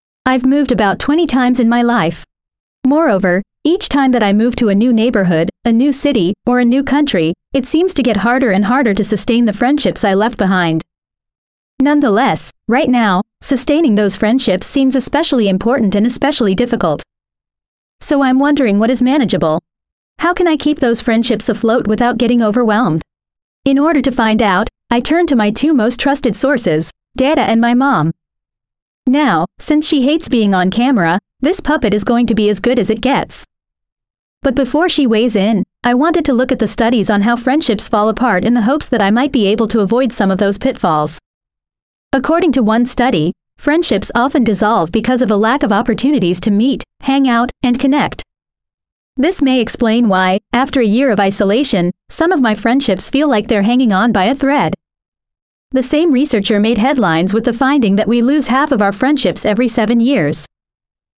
PTE Retell Lecture question, Pearson Test of English, PTE Academic, PTE Preparation Guide, PTE Practice Platform